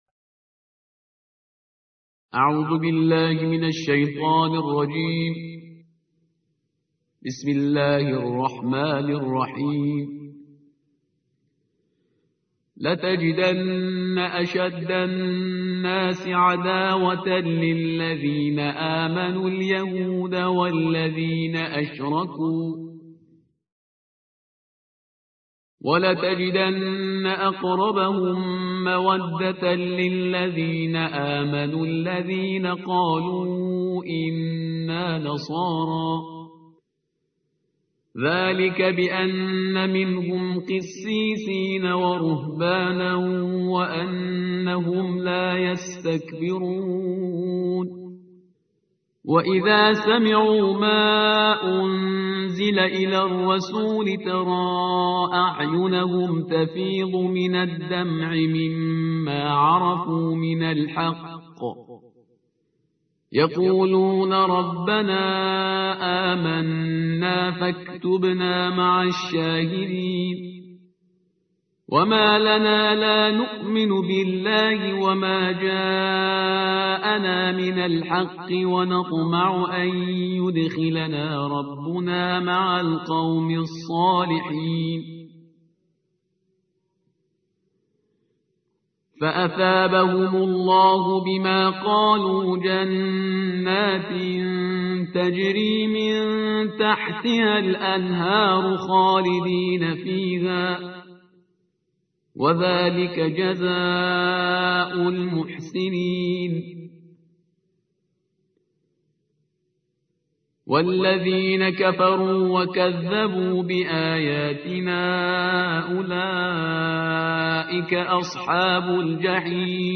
القرآن الكريم: تلاوة الجزء السابع من القرآن الكريم ....